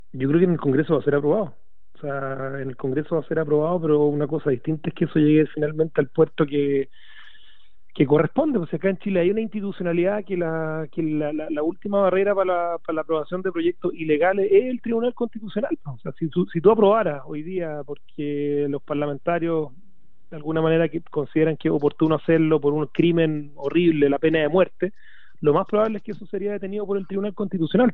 En su entrevista con radio Pauta, el Presidente de la UDI también lamentó que existan parlamentarios de su partido que apoyen el proyecto.